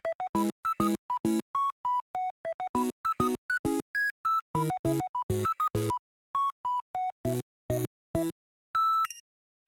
Preview_Click.wav